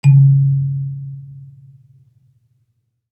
kalimba_bass-C#2-pp.wav